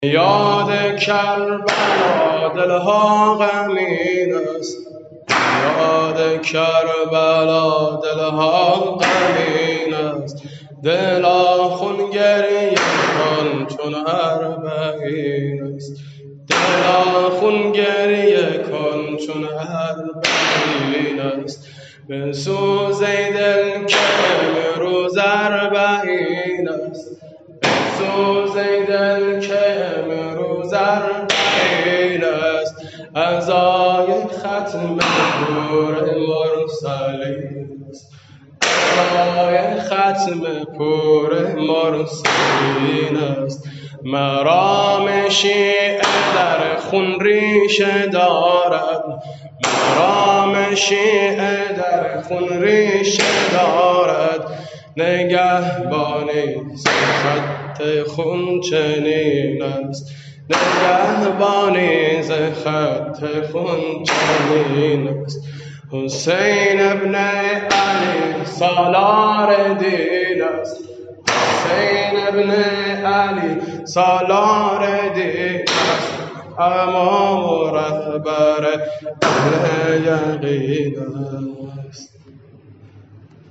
مداحی اربعین حسینی